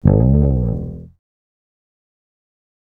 E S SLIDE UP.wav